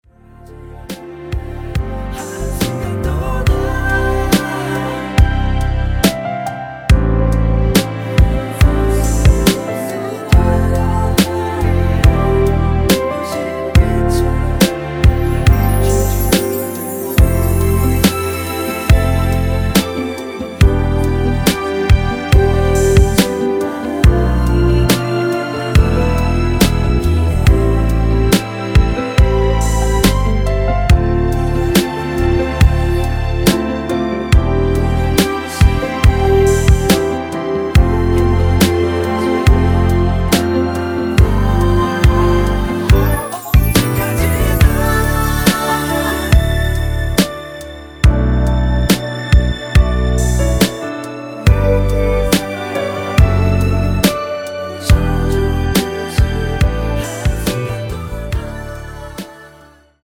원키에서(+1)올린 코러스 포함된 MR입니다.
◈ 곡명 옆 (-1)은 반음 내림, (+1)은 반음 올림 입니다.
앞부분30초, 뒷부분30초씩 편집해서 올려 드리고 있습니다.